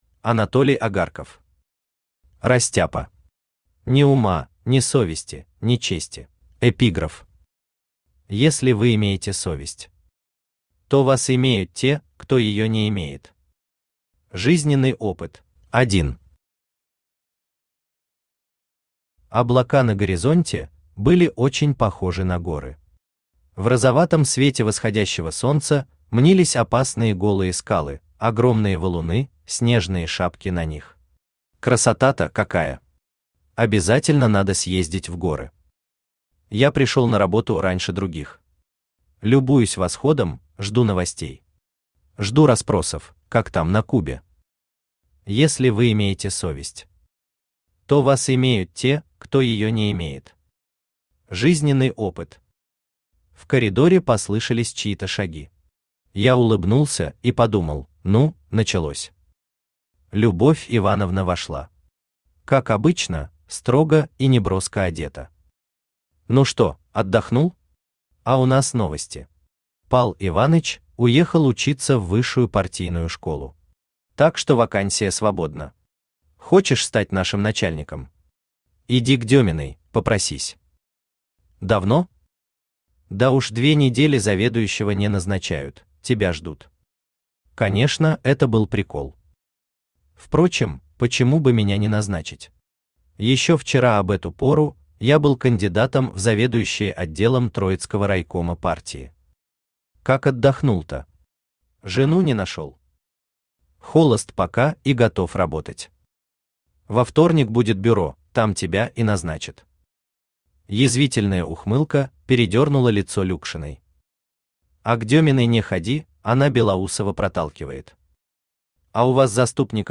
Аудиокнига Растяпа. Ни ума, ни совести, ни чести | Библиотека аудиокниг
Ни ума, ни совести, ни чести Автор Анатолий Агарков Читает аудиокнигу Авточтец ЛитРес.